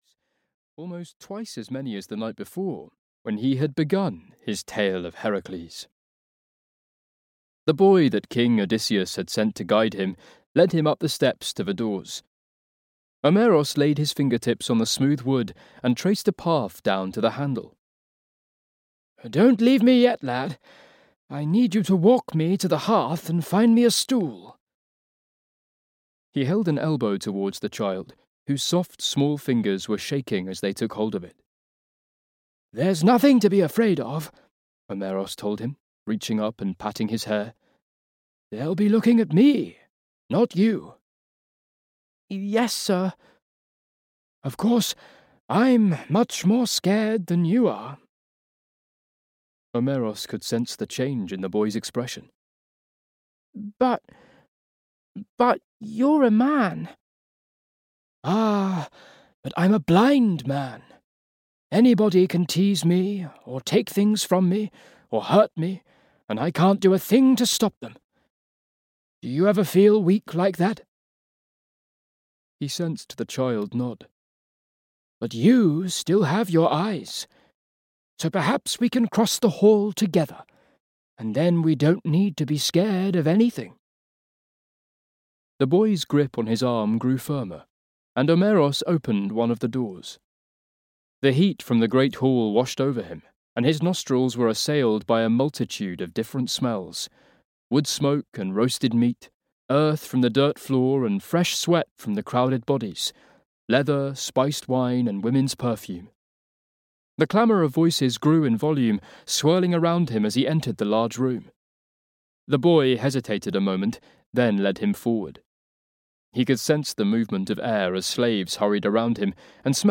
Wrath of the Gods (EN) audiokniha
Ukázka z knihy